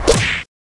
嗖的音效
描述：嗖的音效。
标签： 碰撞 砰的一声
声道立体声